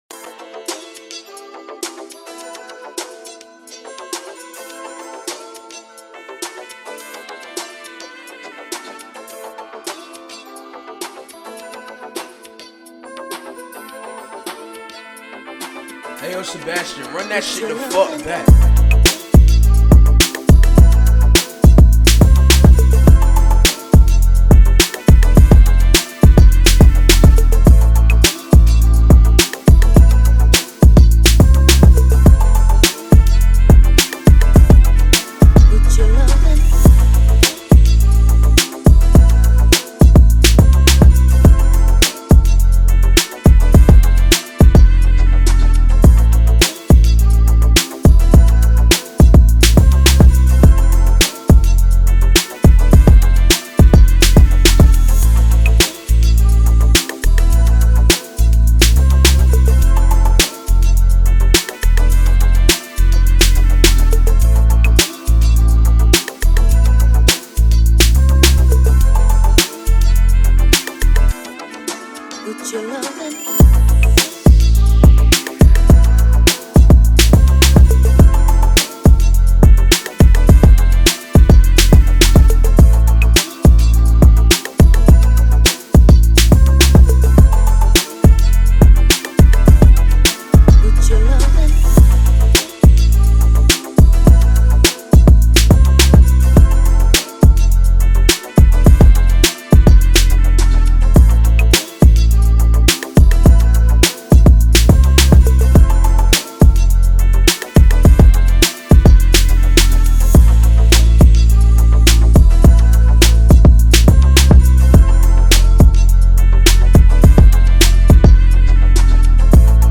2024 in Official Instrumentals , Rap Instrumentals